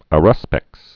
(ə-rŭspĕks)